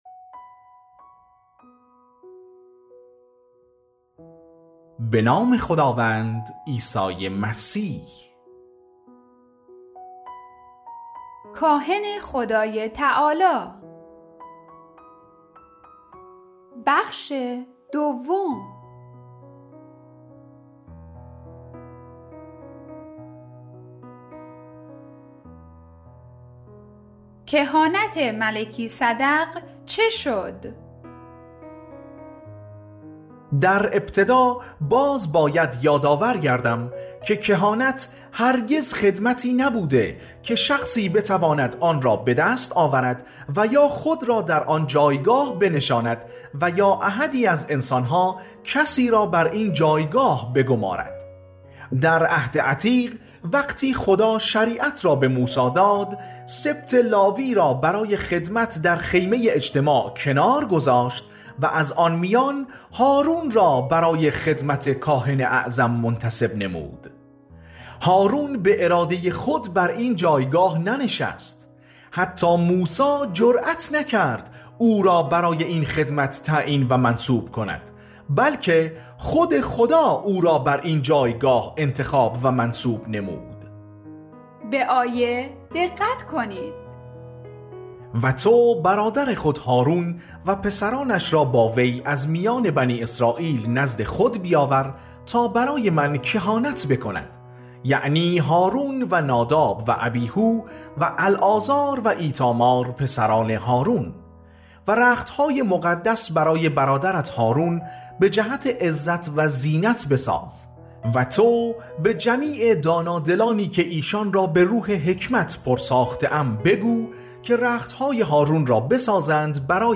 پخش آنلاین و دانلود کتاب صوتی کاهن خدای تعالی